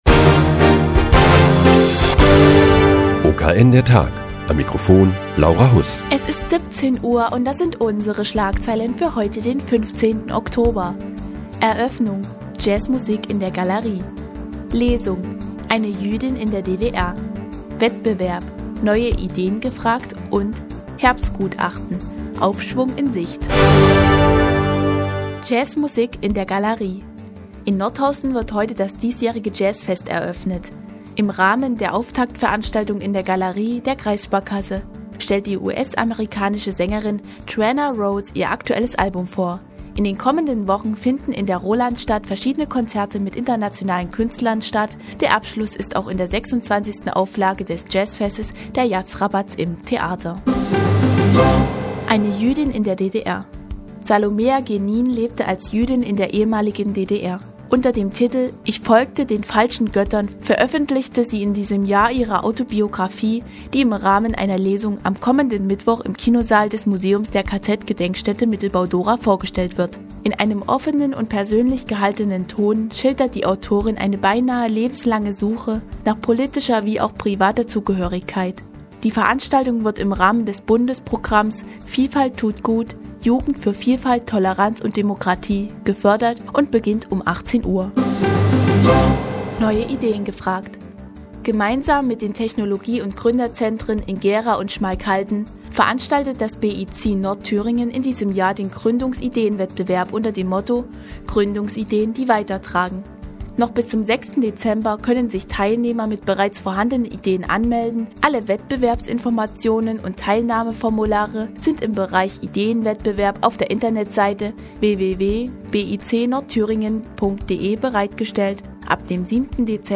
Die tägliche Nachrichtensendung des OKN ist nun auch in der nnz zu hören. Heute geht es um Jazzmusik in der Galerie der Kreissparkasse und um eine Lesung in der KZ-Gedenkstätte Mittelbau-Dohra.